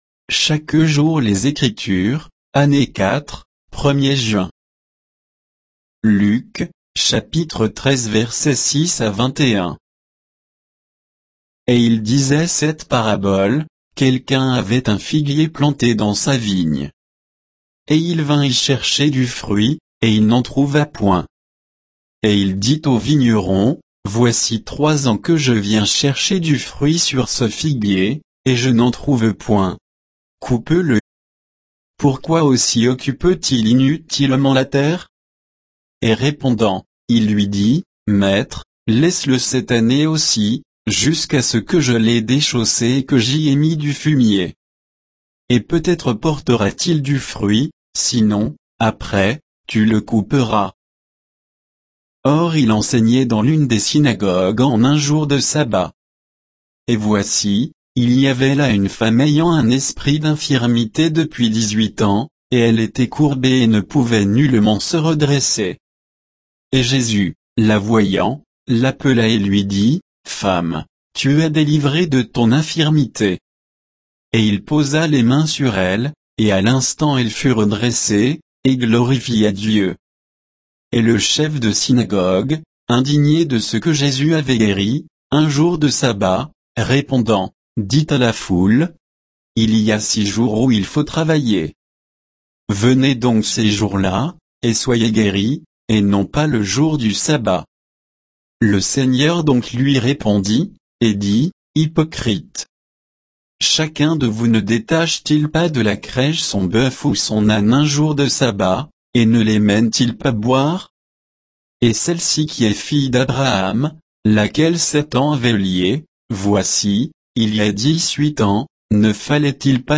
Méditation quoditienne de Chaque jour les Écritures sur Luc 13